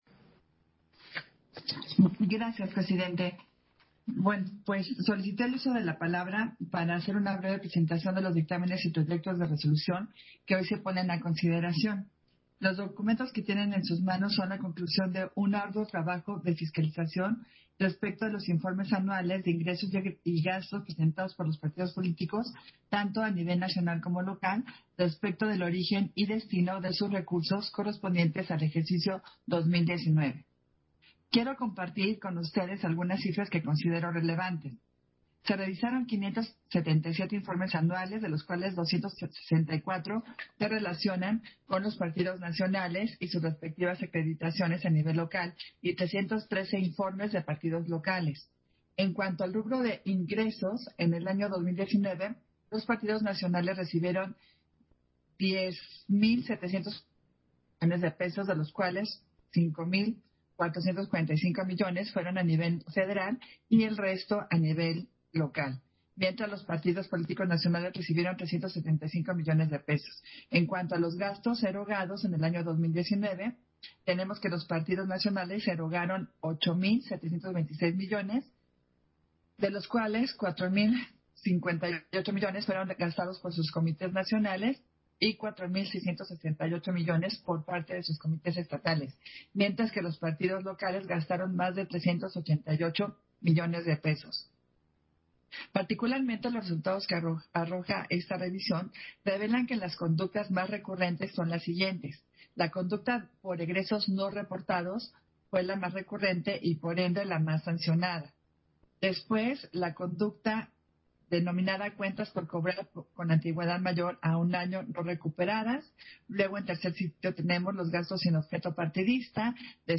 151220_AUDIO_CONSEJERA-ADRIANA-FAVELA-PUNTO-6-SESIÓN-ORDINARIA
Intervención de Adriana Favela en el punto relativo respecto de las irregularidades encontradas en el dictamen consolidado de la revisión de informes anuales de ingresos y gastos de partidos políticos del ejercicio 2019